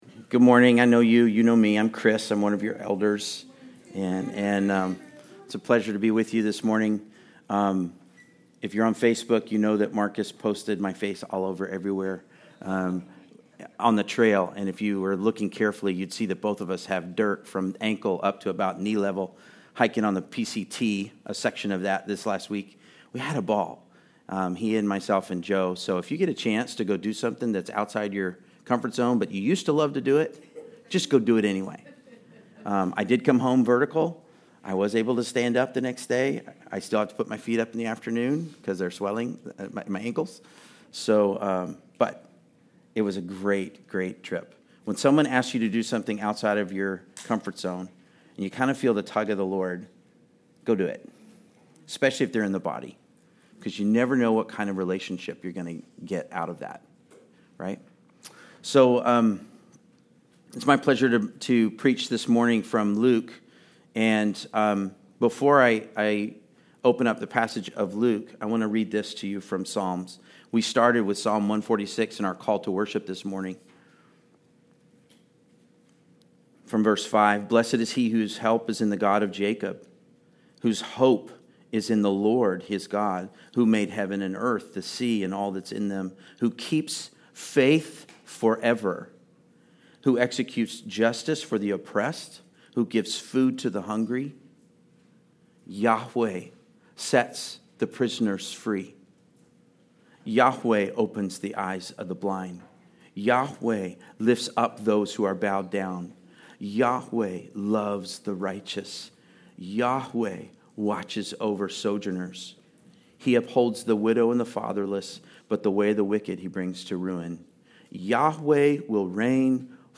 Luke 4:16-30 Service Type: Sunday Service Related « That’s so tempting….